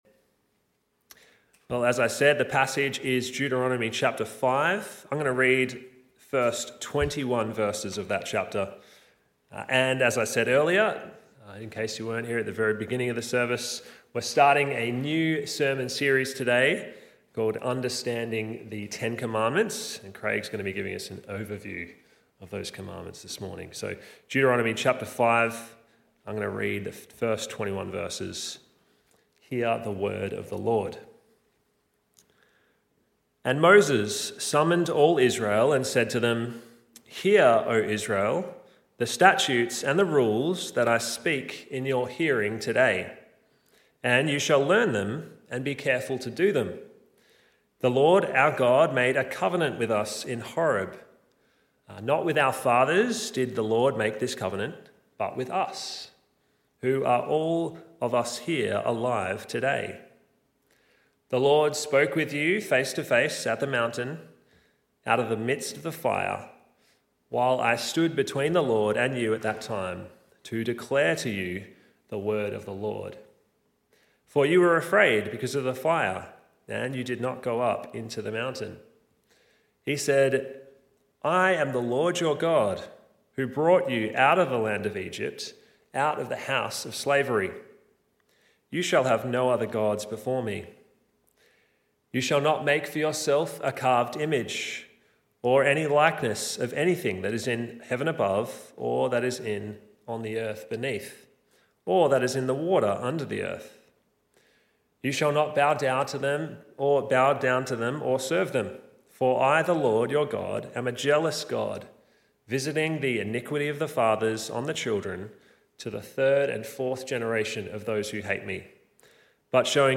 Service Type: Morning Service